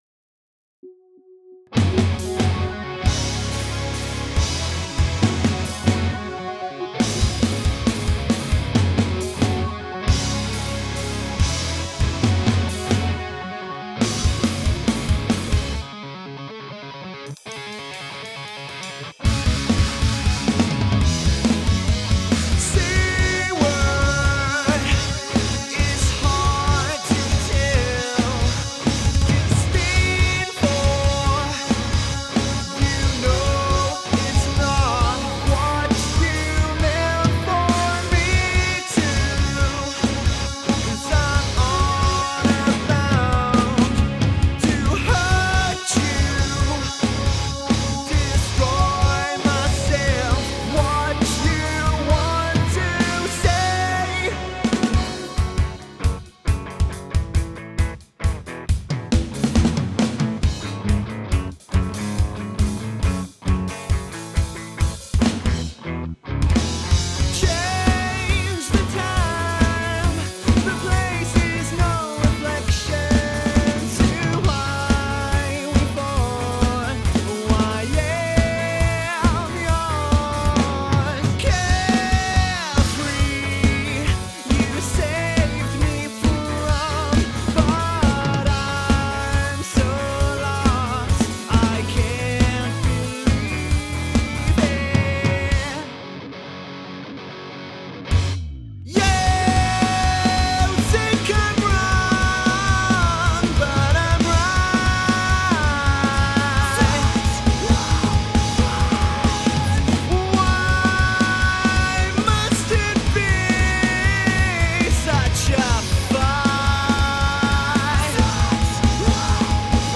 Alt rock mix help- Version 2!
Ok so i've tried to give the mix a bit more depth and give the drums a bit more life.